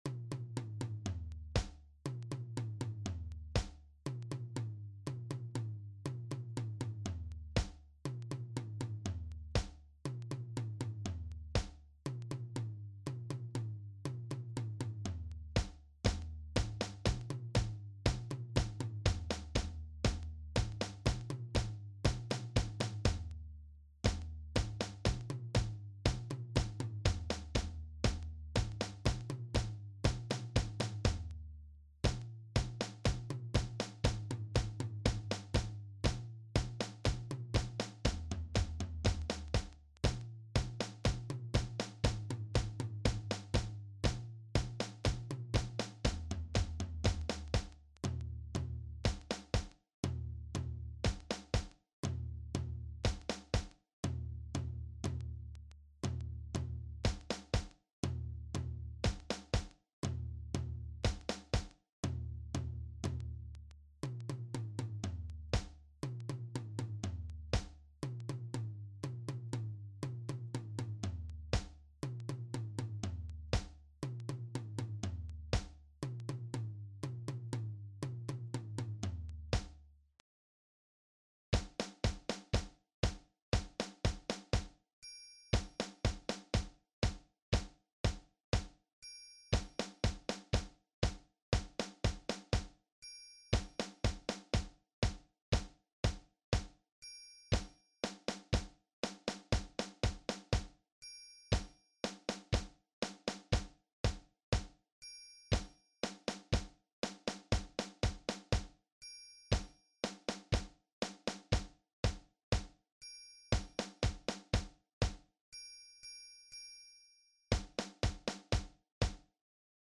Junior Percussion